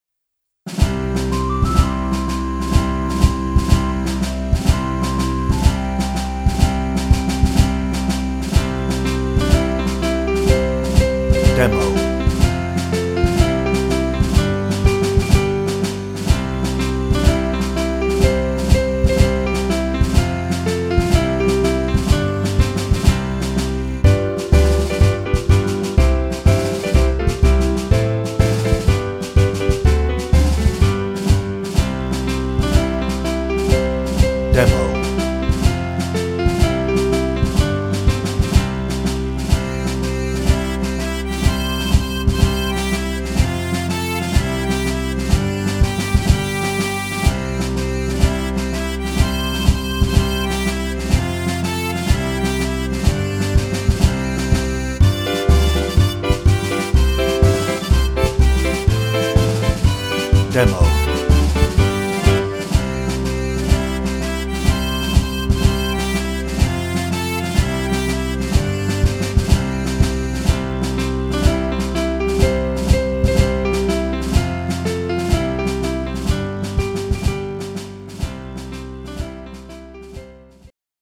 No ref. vocal
Instrumental